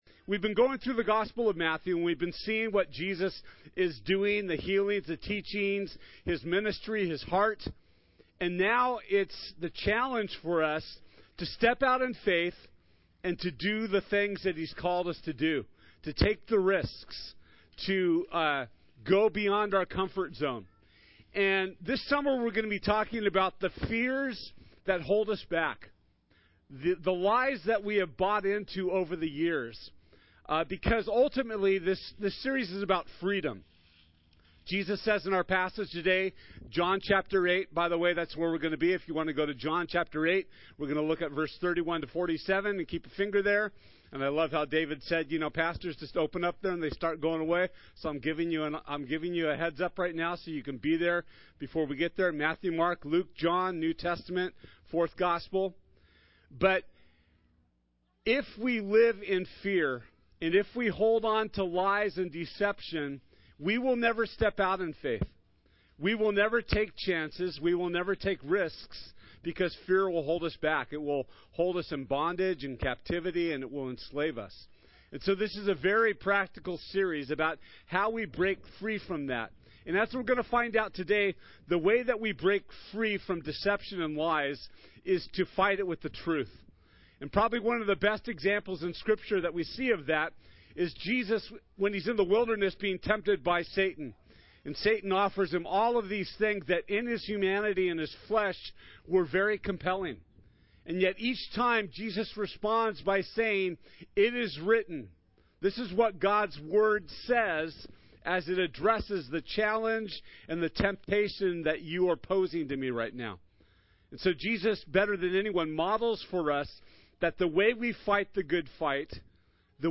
John 8:31-47 Service Type: Sunday This Sunday we’ll be launching a new summer teaching series called “Free Indeed!”